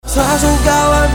• Качество: 320, Stereo
поп
мужской вокал
красивый мужской голос
веселые
dance